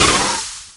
TURBO3.mp3